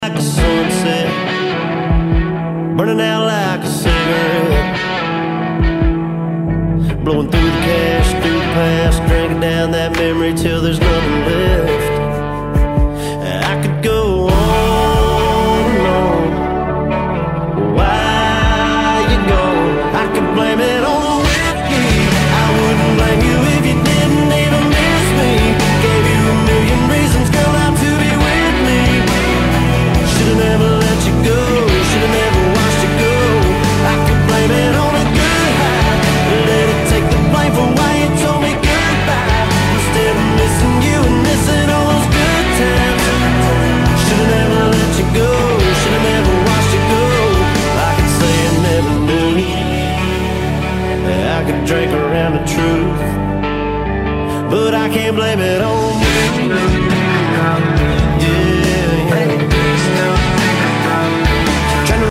454 big block for ya sound effects free download